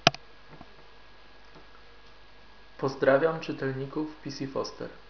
Na koniec przykładowe nagranie w odległości 50 cm od mikrofonu.
Sprawdźcie jak spisuje się opcja dyktafonu.